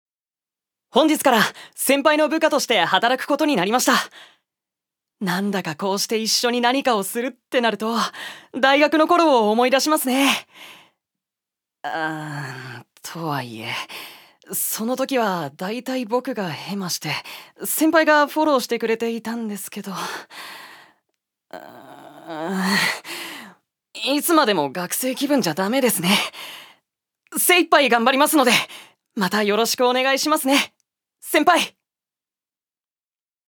所属：男性タレント
セリフ１